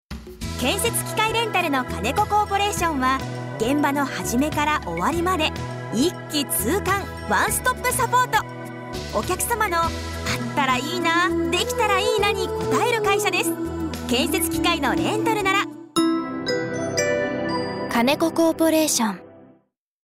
FM石川にてラジオCM放送中！